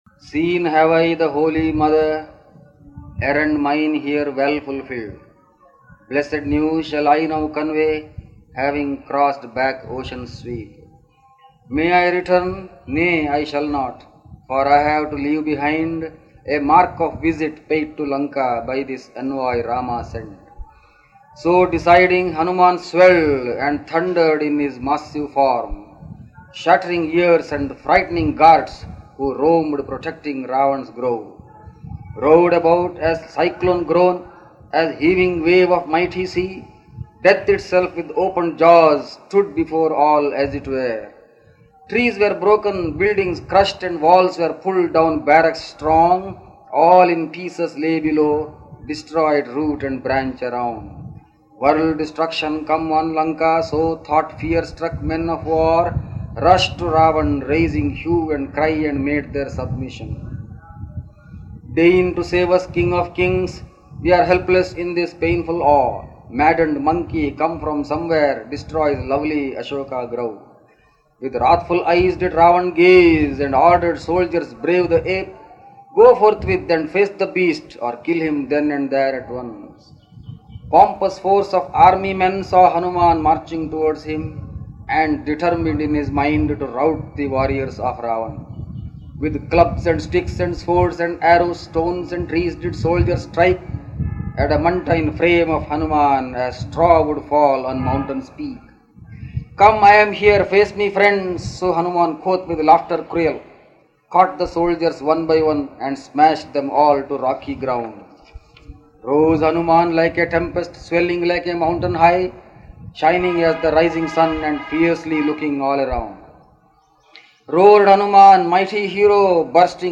Swami Krishnananda reading his poetry